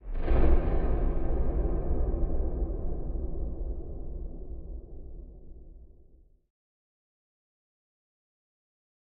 scpcb-godot/SFX/Ambient/Zone1/Ambient2.ogg at ae8b17a347ad13429a7ec732a30ac718cea951e4
Ambient2.ogg